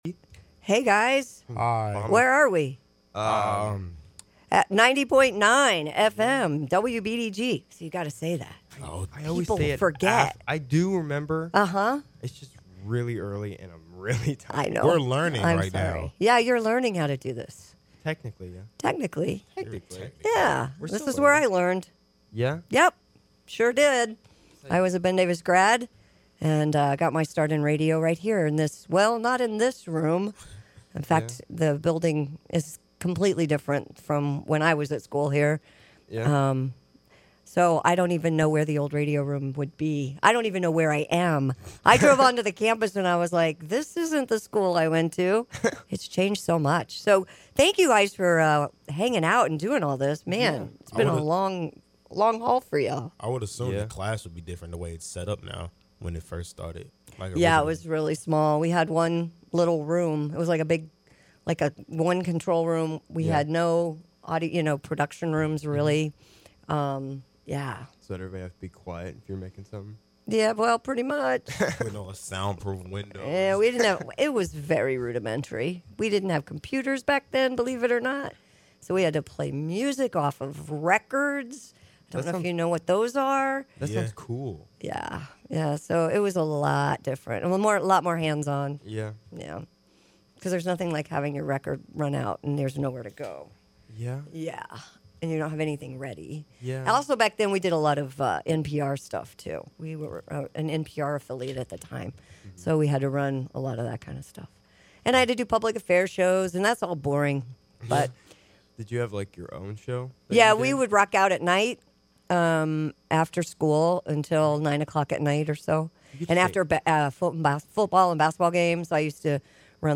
She ends up interviewing them!